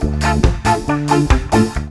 SPEEDLOOP1-R.wav